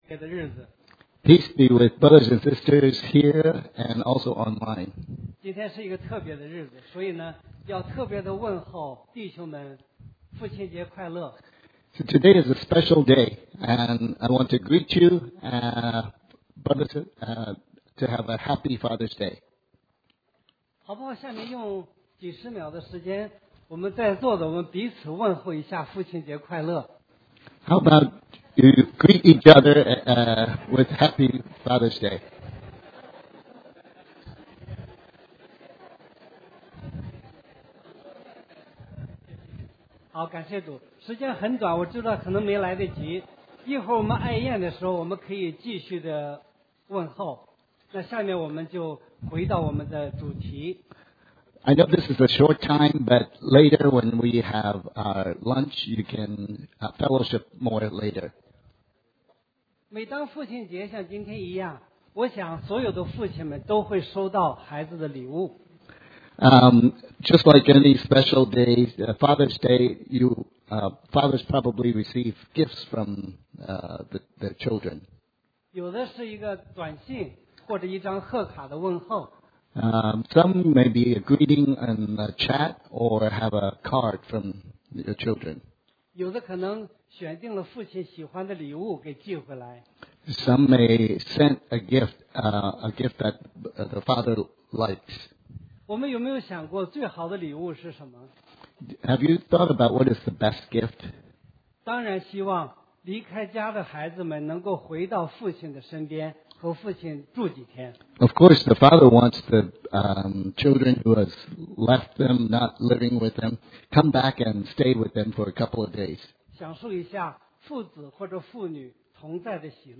Audio Type: Chinese Sermon